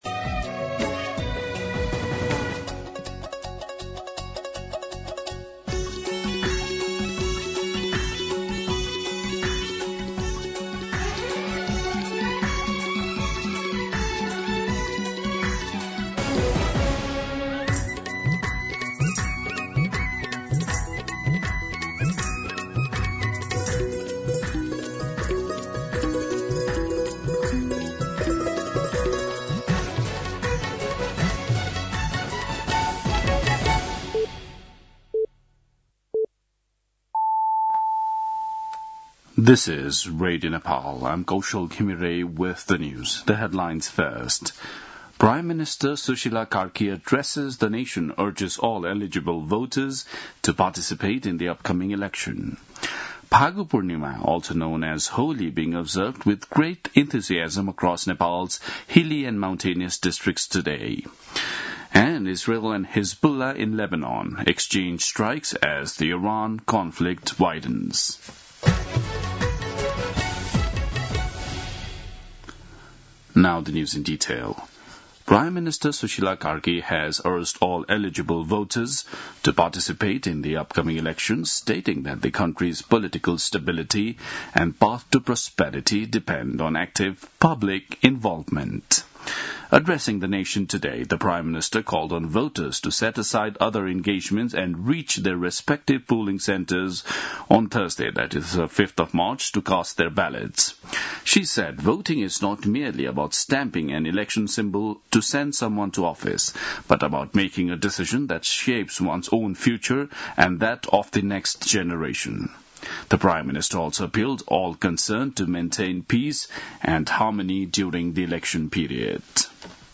दिउँसो २ बजेको अङ्ग्रेजी समाचार : १८ फागुन , २०८२
2pm-English-News-11-18.mp3